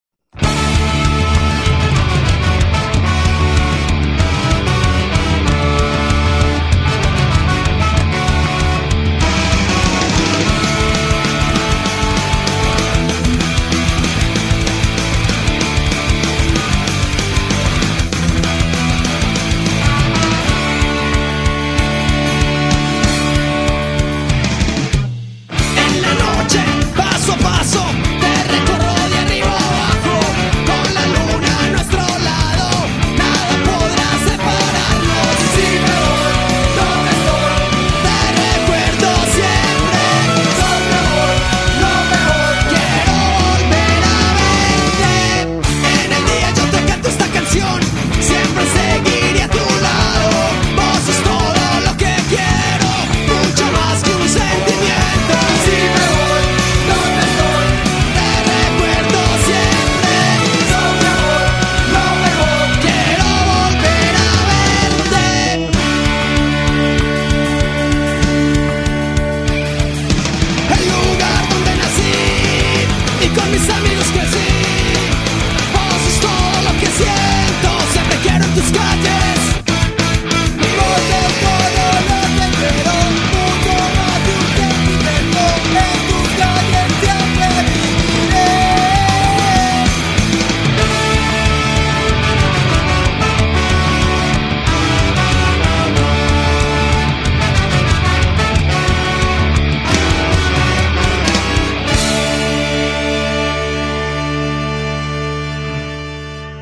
Skacore